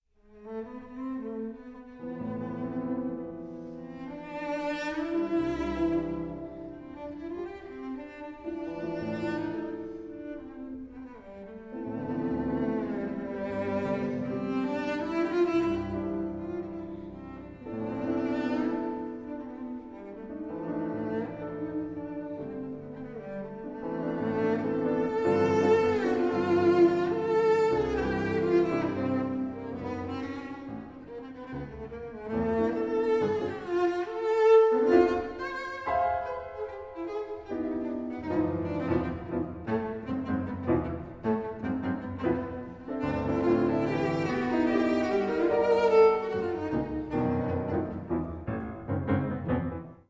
piano
Sonata in F minor for cello and piano, Op 26 No 1